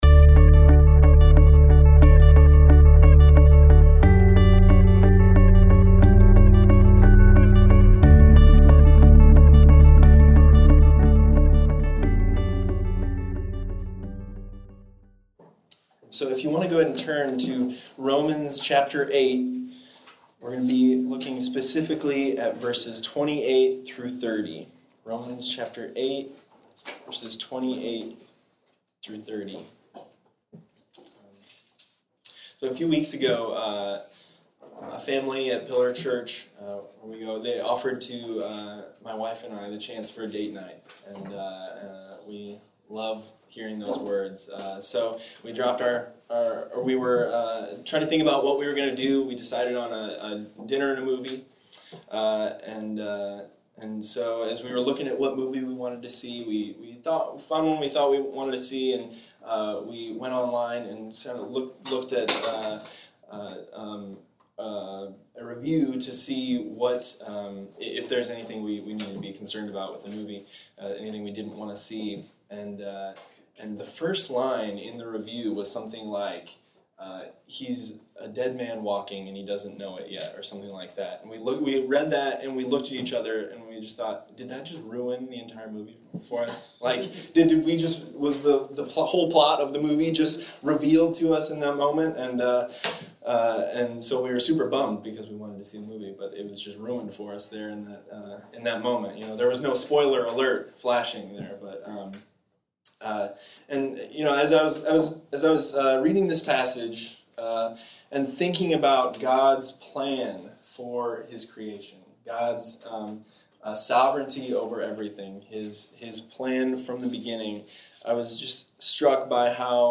Romans 8:28-30 Download file | Play in new window | Duration: 34:52 Subscribe: Apple Podcasts | Google Podcasts | Spotify Topics Advent Chain of Redemption Foreknowledge Grace Regeneration Share this Sermon